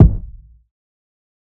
TC2 Kicks7.wav